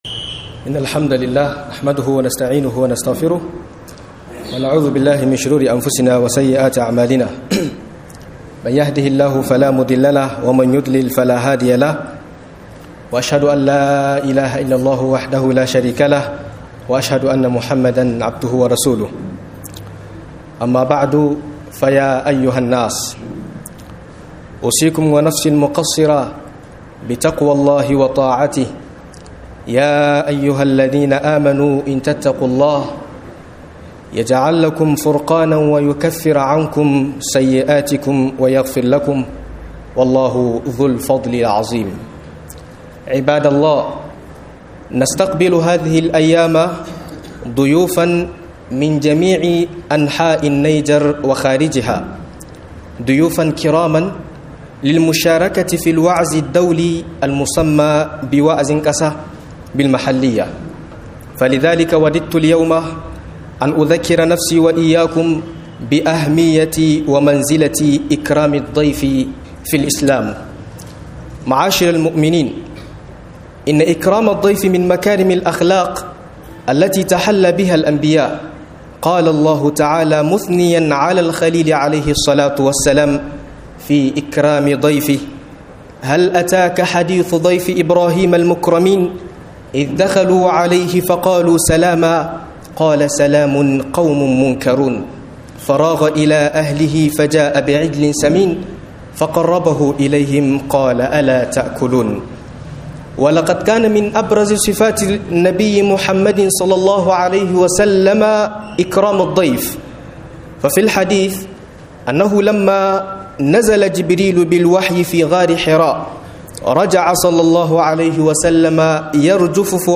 Girmama Bako (Mahimmancin sa da ladubban sa) - MUHADARA